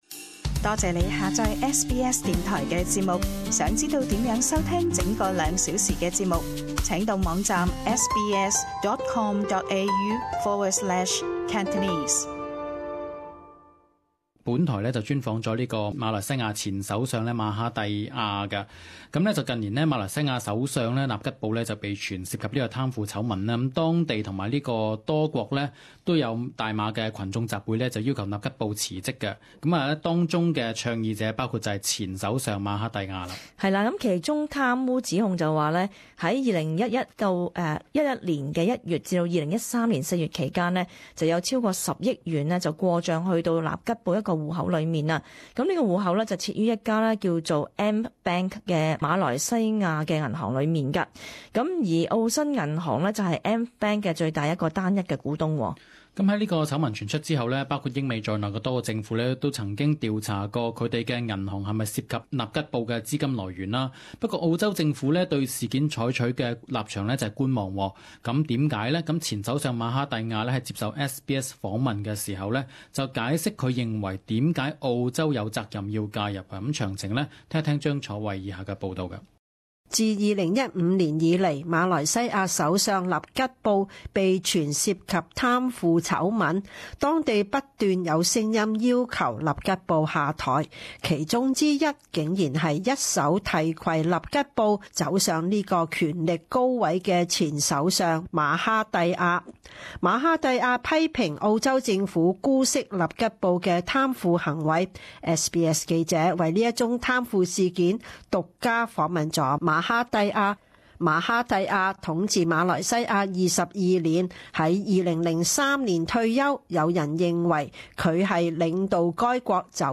時事報導：澳州避捲入納吉布貪腐醜聞？